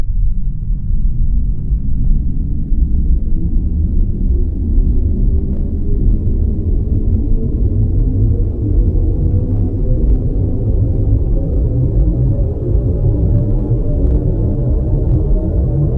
acceleration-old1.ogg